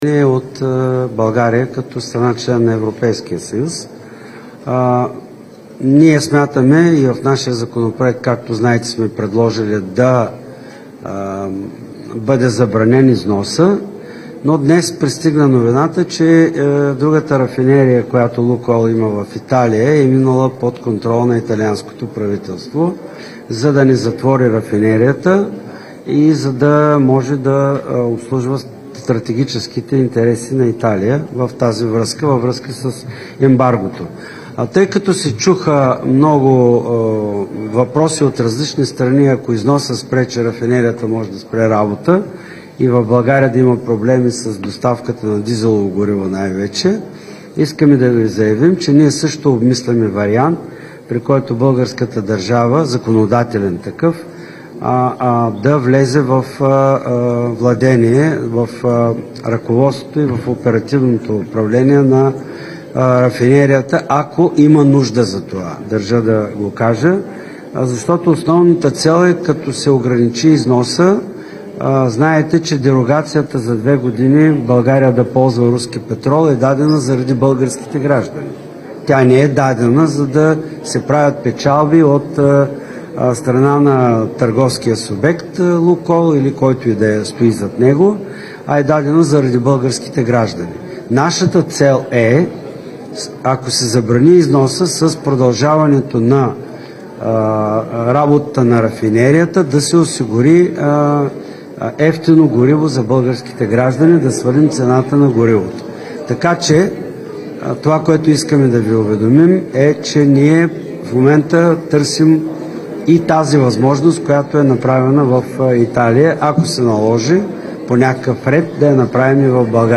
10.50  - Брифинг на президентът Румен Радев след тържественото отбелязване на 80-ата годишнина от основаването на Националната спортна академия „Васил Левски" (НСА). По време на официалната церемония държавният глава удостои НСА с Почетен знак на президента. - директно от мястото на събитието (аула „Максима" на НСА)